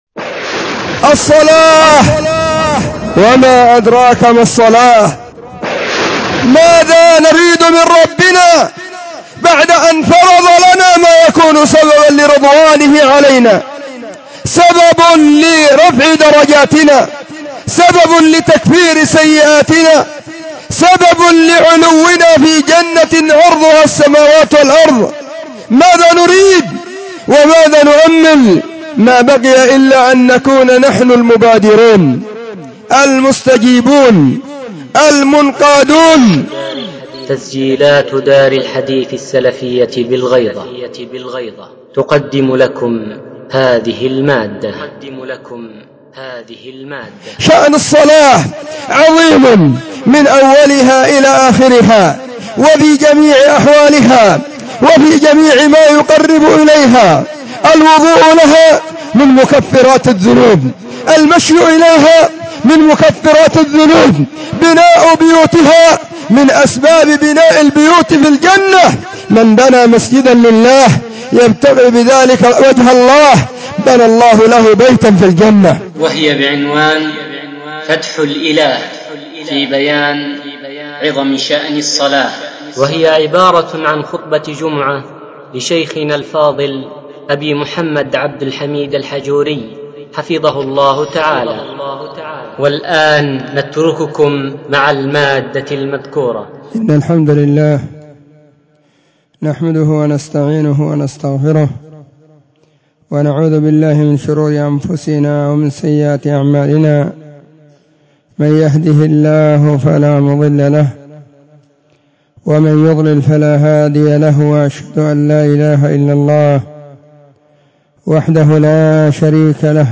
خطبة جمعة بعنوان :فتح الإله في بيان عظم شأن الصلاة [ 10 /صفر/ 1443 هـ]
📢 وكانت في مسجد الصحابة بالغيضة محافظة المهرة – اليمن.
الجمعة 10 صفر 1443 هــــ | الخطب المنبرية | شارك بتعليقك